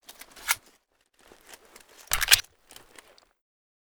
oc33_reload_maggrab.ogg